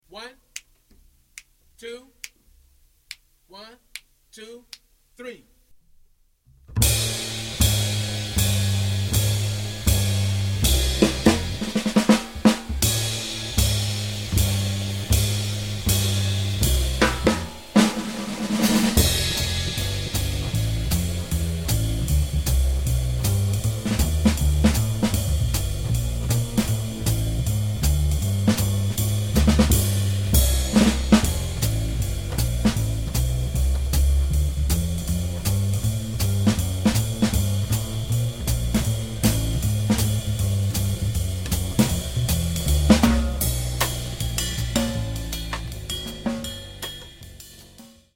A very happy swinging tune with an intricate second part.
bass & drums only